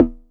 • TR 909 Electronic Tom Sample B Key 02.wav
Royality free tr 909 electronic tom sample tuned to the B note. Loudest frequency: 338Hz